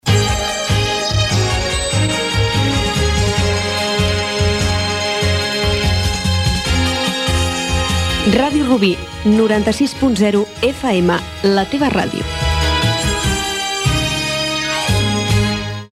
Indicatiu curt de l'emissora.